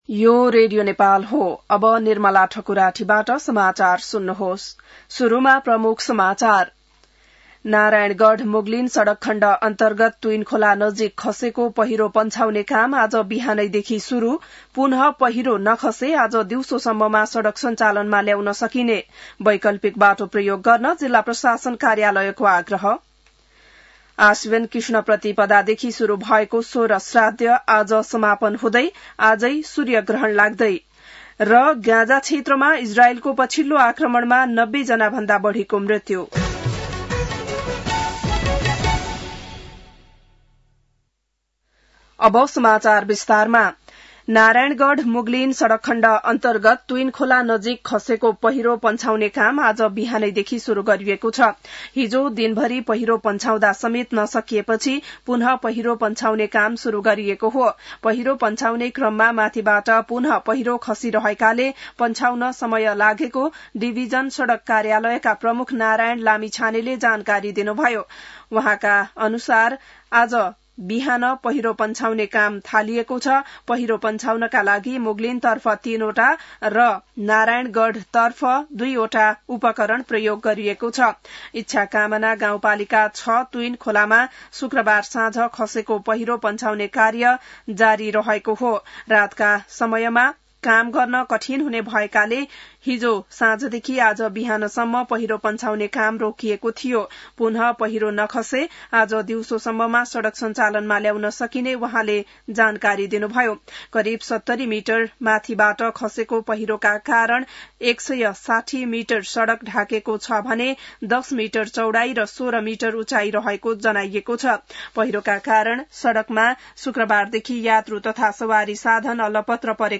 An online outlet of Nepal's national radio broadcaster
बिहान ९ बजेको नेपाली समाचार : ५ असोज , २०८२